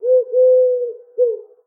sounds_pigeon.ogg